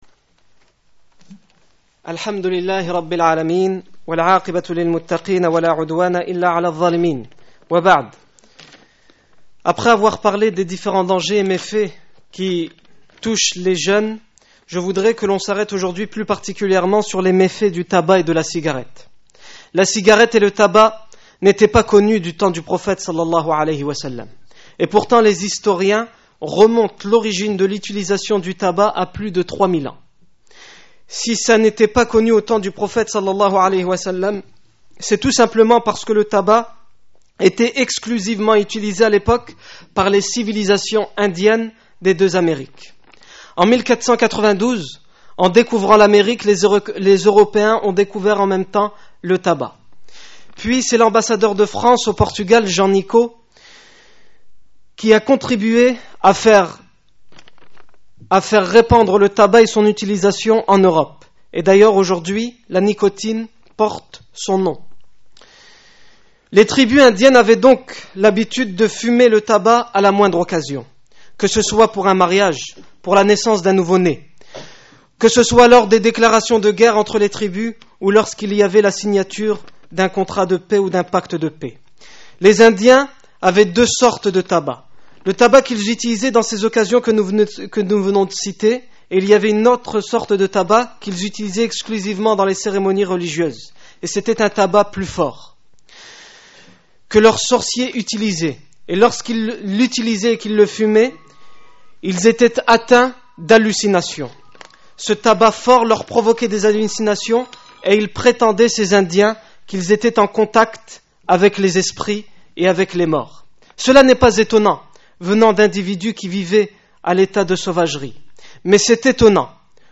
Discours du vendredi